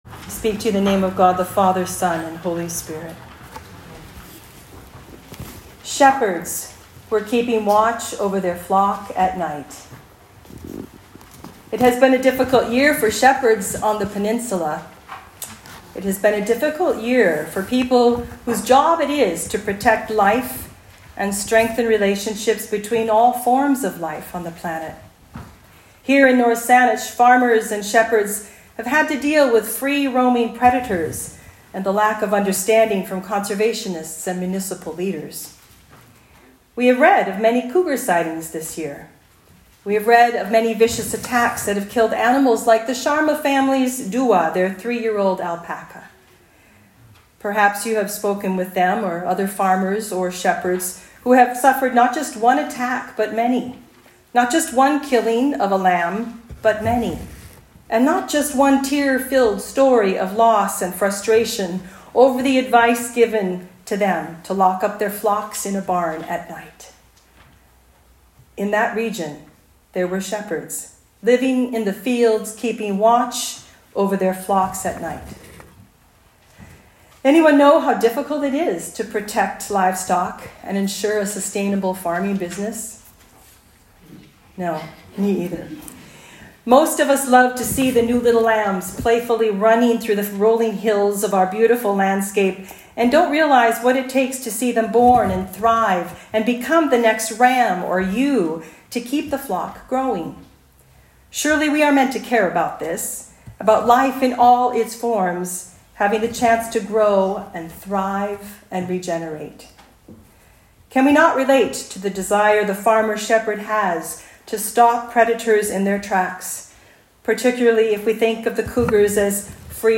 Christmas Eve Talk